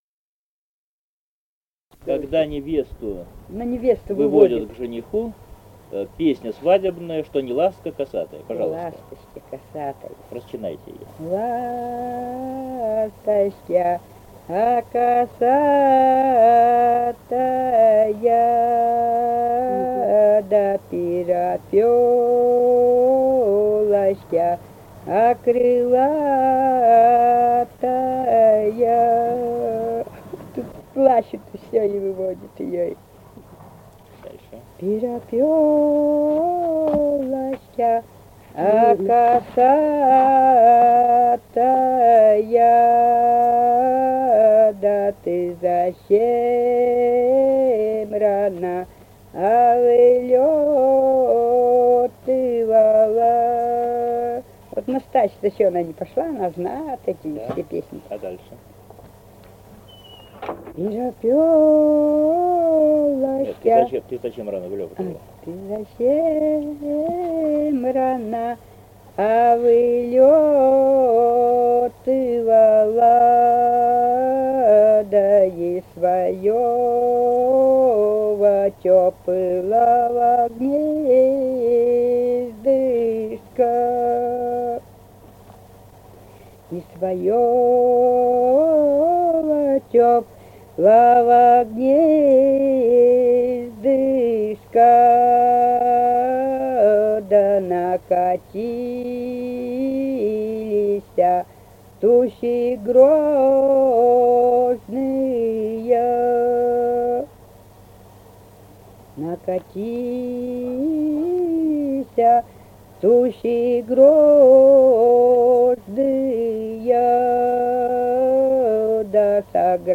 | filedescription = «Ласточка косатая», свадебная, когда невесту выводят к жениху.
в с. Печи, июль 1978.